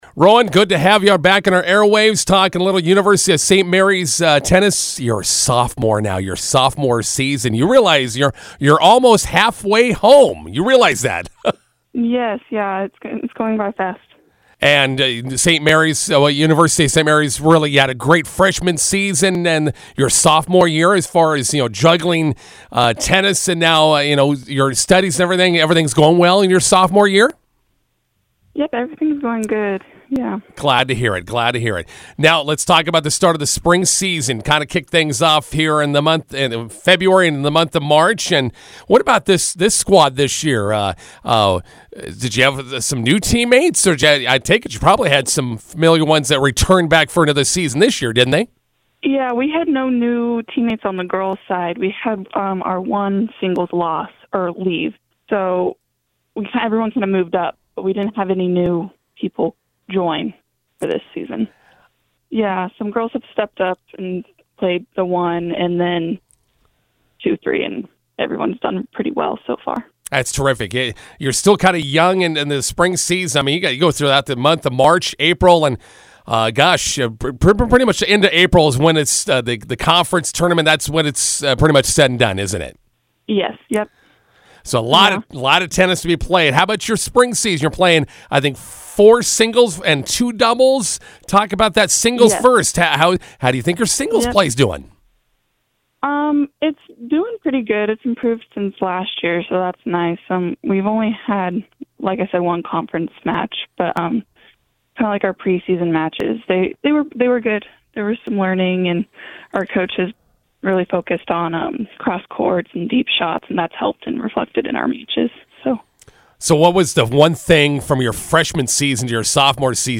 INTERVIEW: University of St. Mary’s spring tennis season is underway.